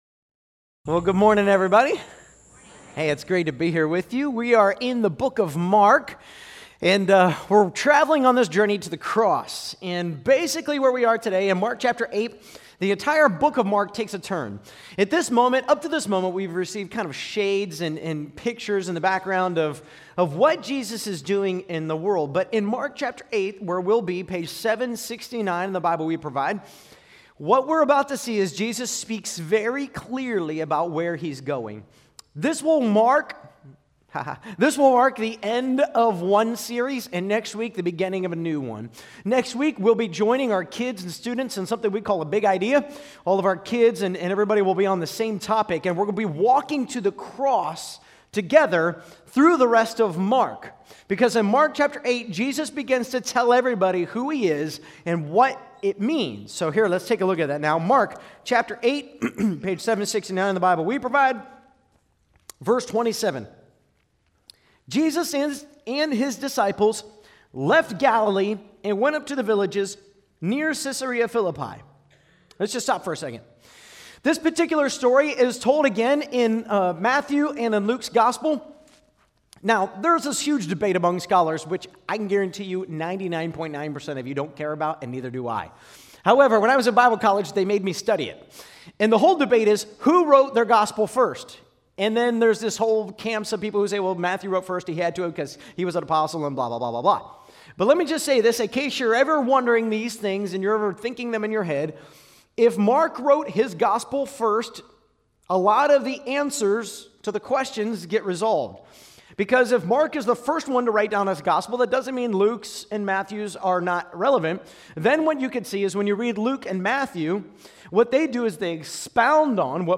Sermons by Kingsway Christian Church